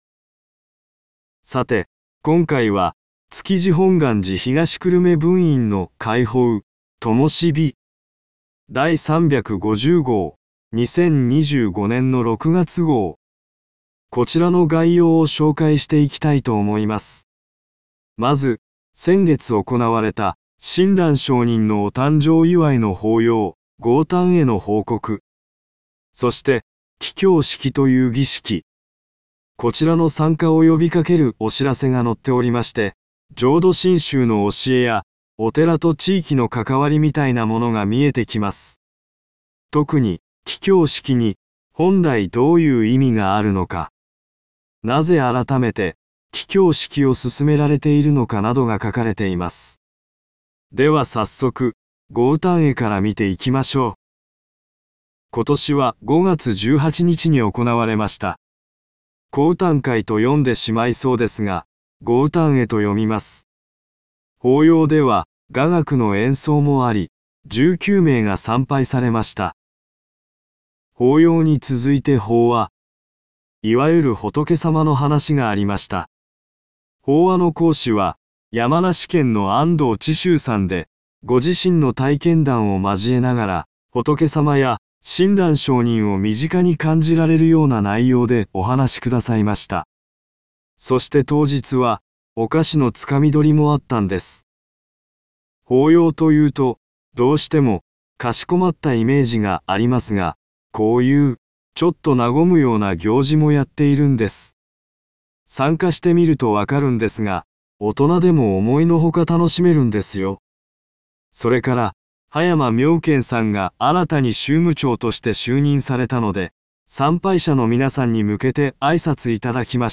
351号（概要朗読）
2025年7月号（概要朗読）